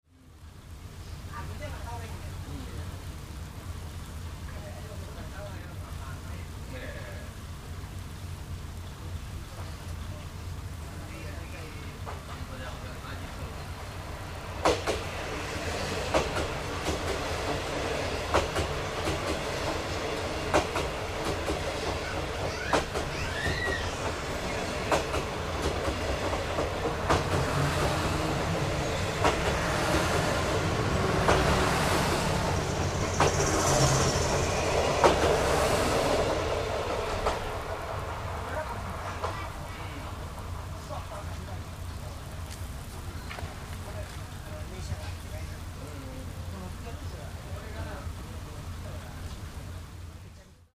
紀勢本線佐奈駅を通過していく９０６D「紀州4号・はまゆう2号」
紀州・はまゆう併結の長い列車を退避。